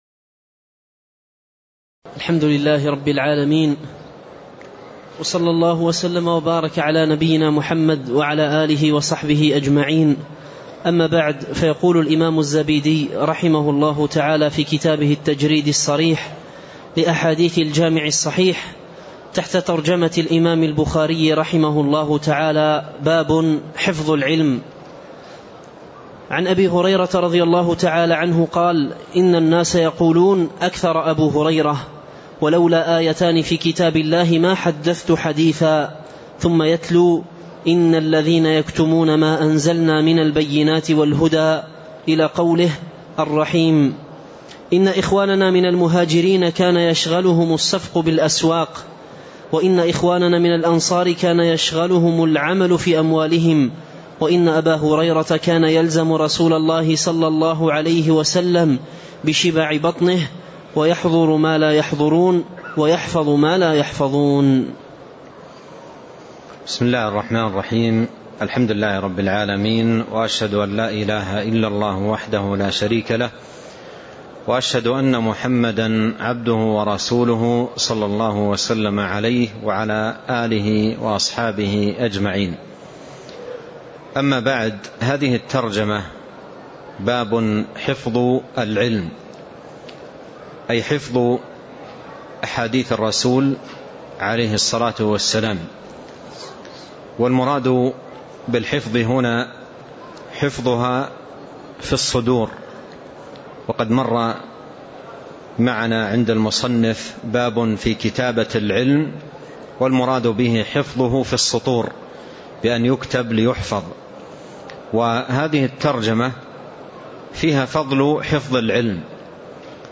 تاريخ النشر ١٧ جمادى الأولى ١٤٣٣ هـ المكان: المسجد النبوي الشيخ